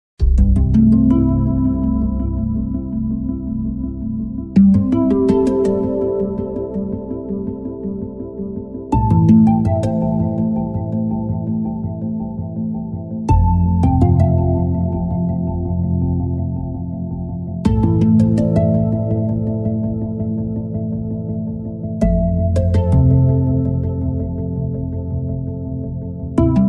• Качество: 128, Stereo
красивые
спокойные
без слов